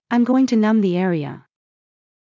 ｱｲﾑ ｺﾞｰｲﾝｸﾞ ﾄｩ ﾅﾑ ｼﾞ ｴﾘｱ